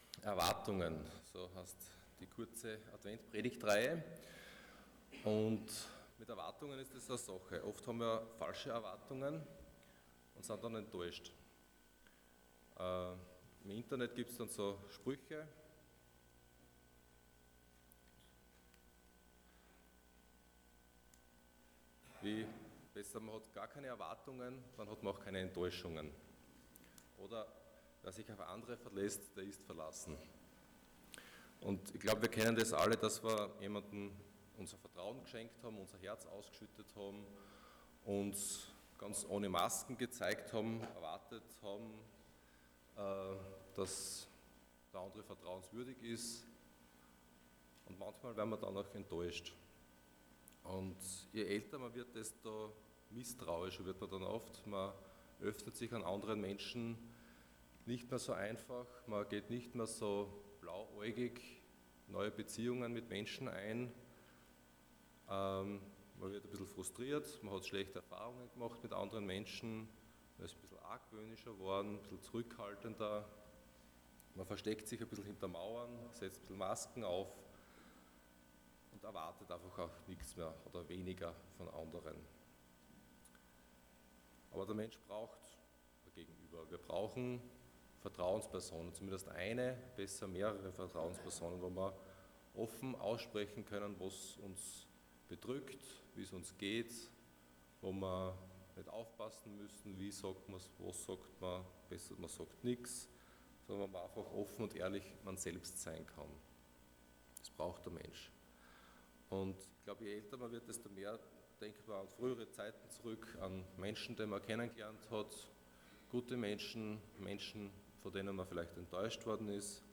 Passage: Matthew 11:2-10 Dienstart: Sonntag Morgen